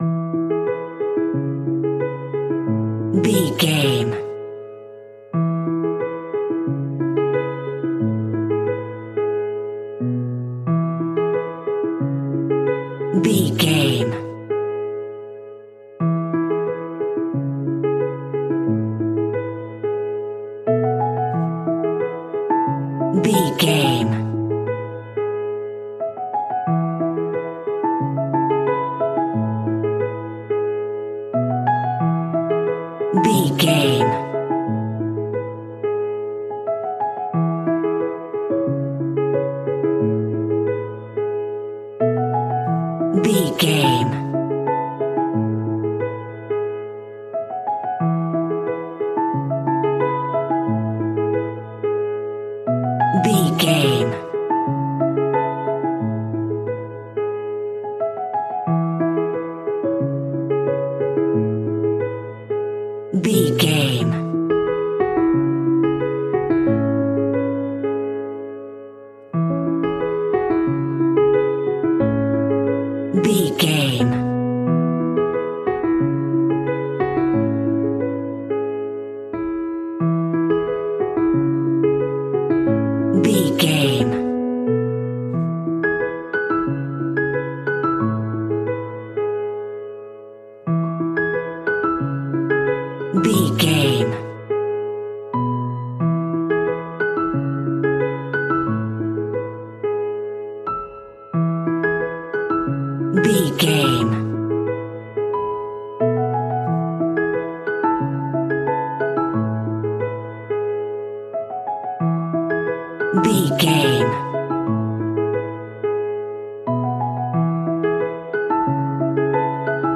Joyful happy moments on a classical piano.
Regal and romantic, a classy piece of classical music.
Ionian/Major
soft